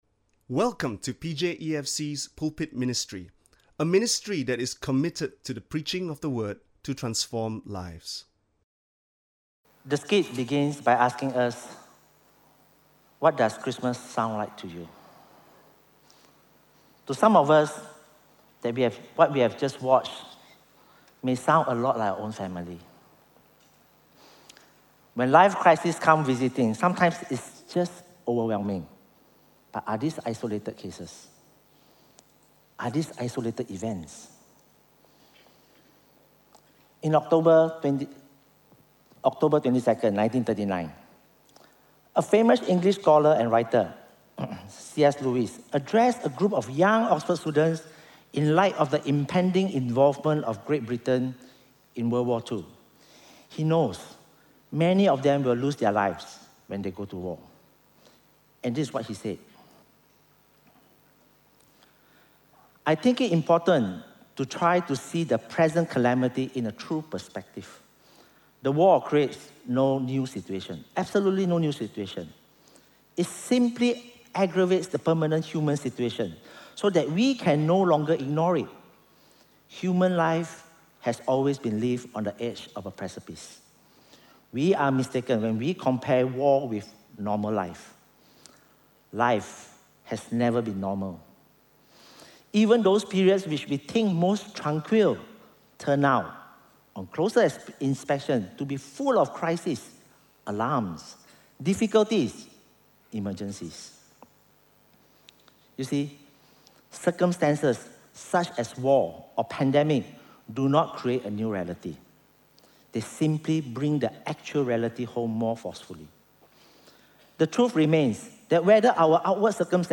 This is a stand-alone sermon in conjunction with Christmas.